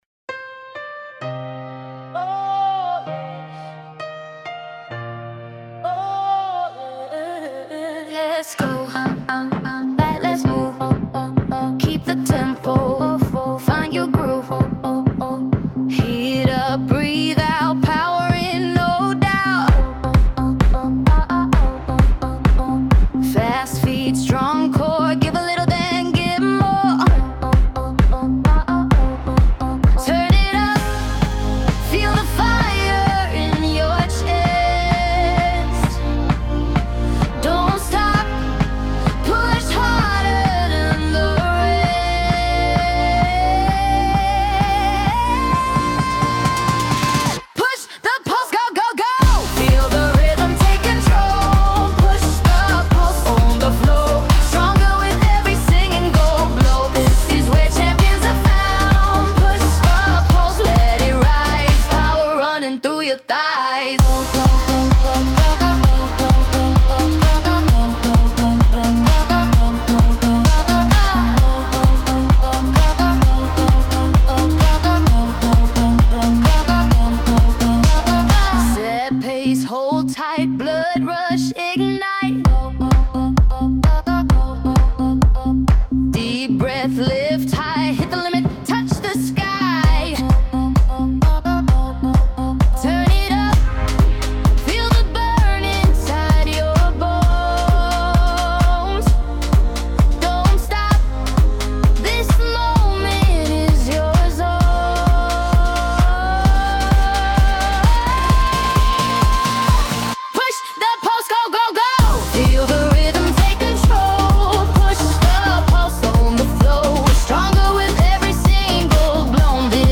Genre: Funk / Disco / Dance / Breakbeats